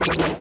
XWing-Laser